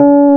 Index of /90_sSampleCDs/Roland L-CD701/KEY_E.Pianos/KEY_Rhodes
KEY RHODS 06.wav